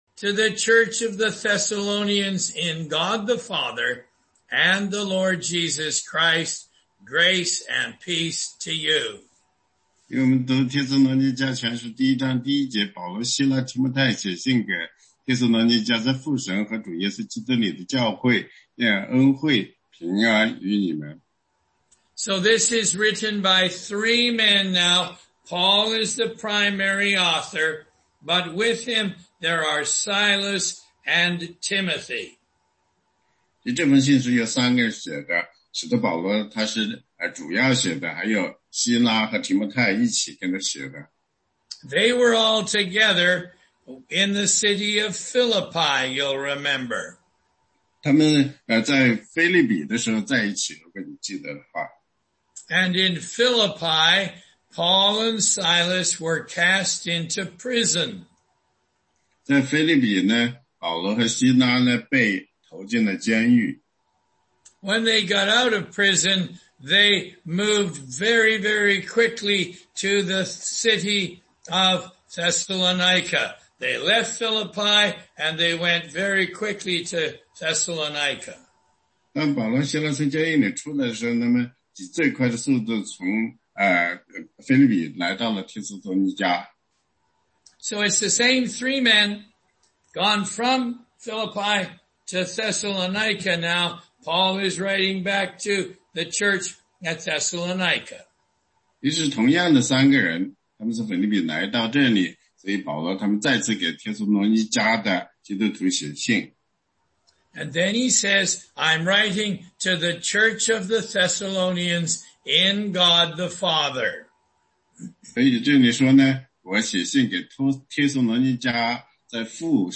16街讲道录音 - 帖撒罗尼迦前书1章
答疑课程